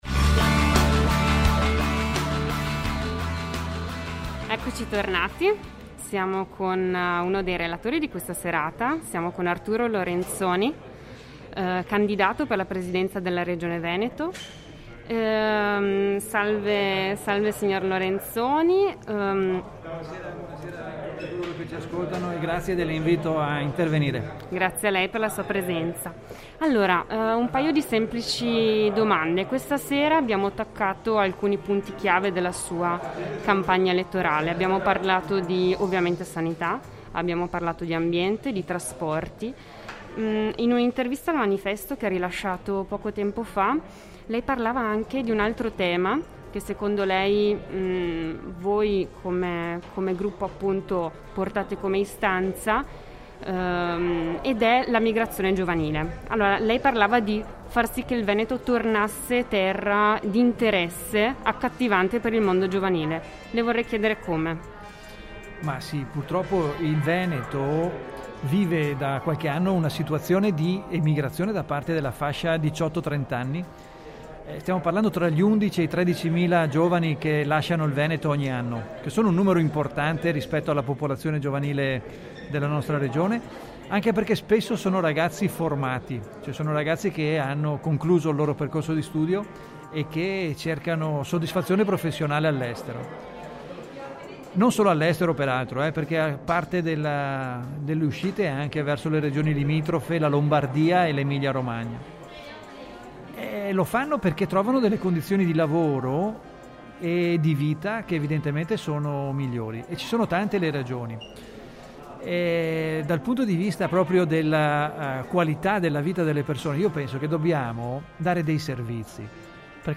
LE INTERVISTE DI RADIO ZAPPA